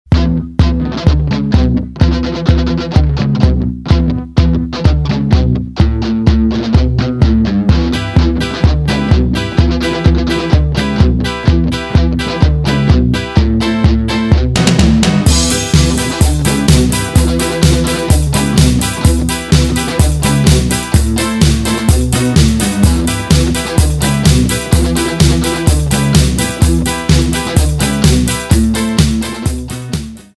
• Качество: 320, Stereo
громкие
мотивирующие
веселые
без слов
Bass
русский рок
Слегка усиленная вырезка из начала трека.